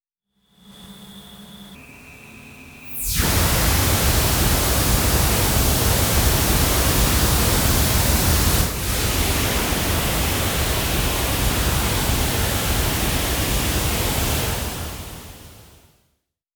However, the smudges on the facade, presumably washed away graffiti, is visually reminiscent of audio tracks. In this “research station”, the pictoral information of the coated wall parts is read out and reproduced as sound.
structuralnoise_part.wav